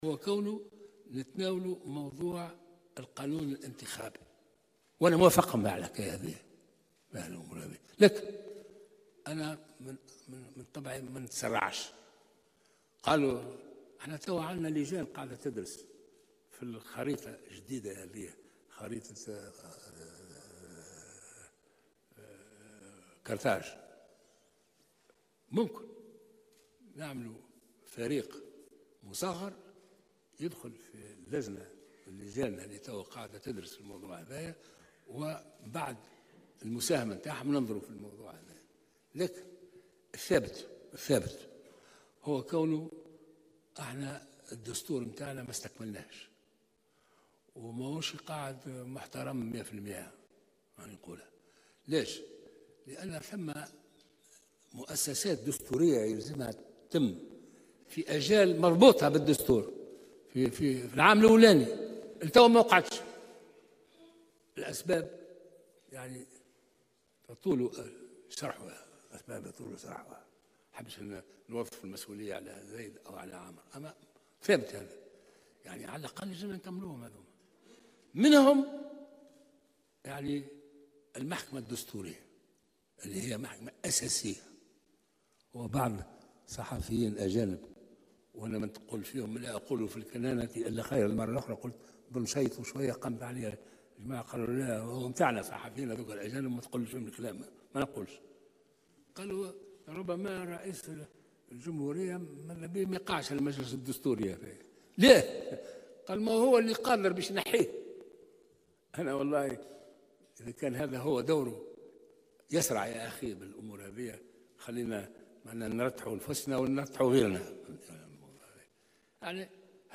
وجاء ذلك بمناسبة خطاب رئيس الجمهورية الباجي قايد السبسي في الذكرى 62 لعيد الاستقلال بقصر قرطاج.